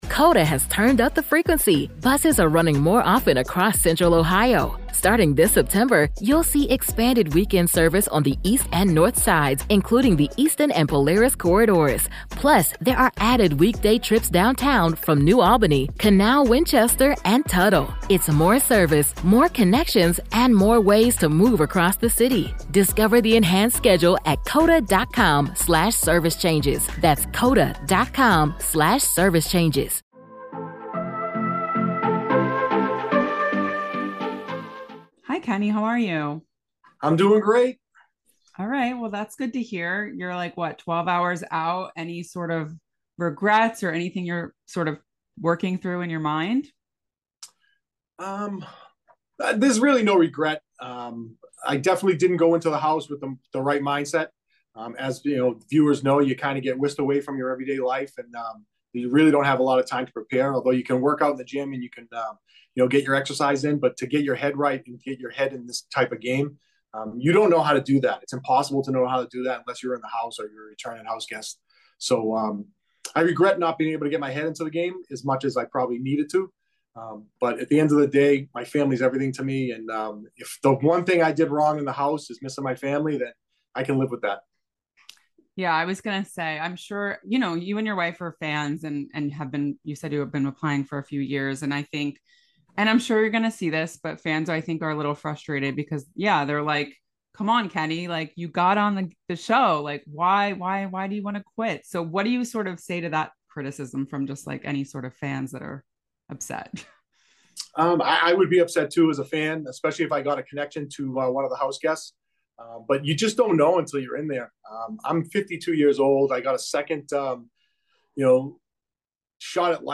Big Brother 26 Exit Interview